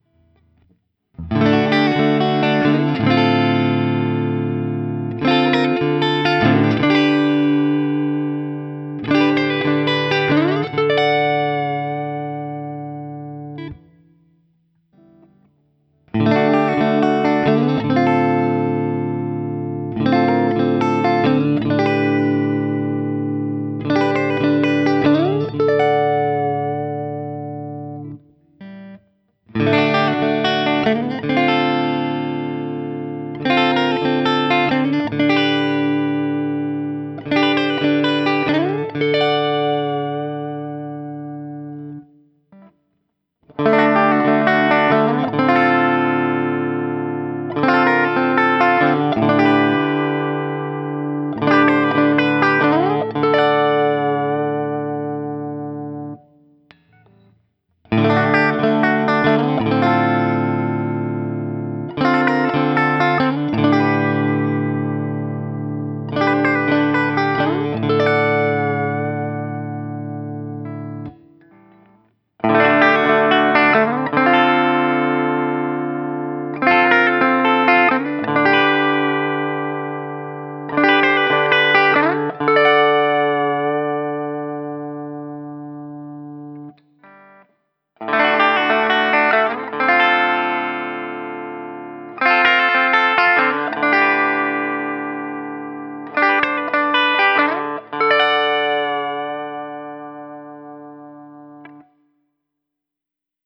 ODS-100
D-Shape Chords
For these recordings I used my normal Axe-FX II XL+ setup through the QSC K12 speaker recorded direct via USB into my Macbook Pro using Audacity.
Thus, each recording has seven examples of the same riff.